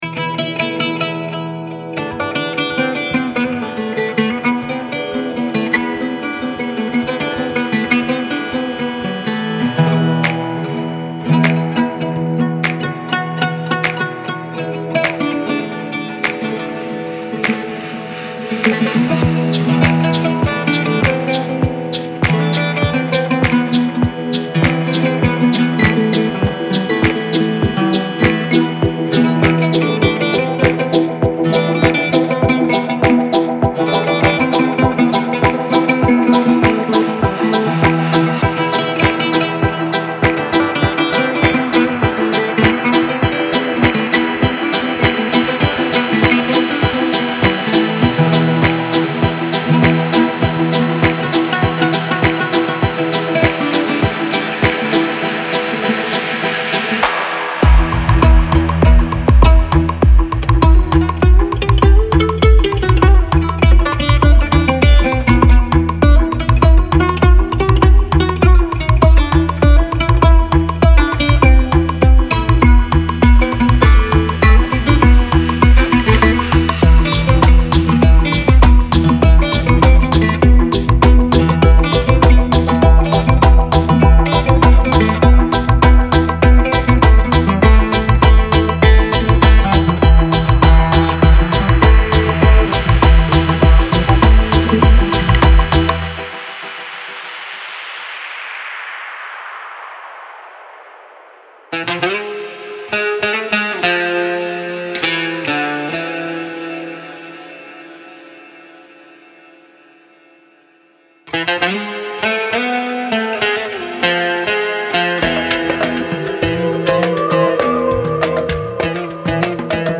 锡塔尔琴，小提琴，吉他和锋利
的凹槽的活圈带来必要的神秘能量。
.036xOud环
·完全混合和掌握